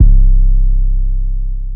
808 (BUTTERFLY EFFECT C).wav